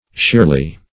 sheerly - definition of sheerly - synonyms, pronunciation, spelling from Free Dictionary Search Result for " sheerly" : The Collaborative International Dictionary of English v.0.48: Sheerly \Sheer"ly\, adv. At once; absolutely.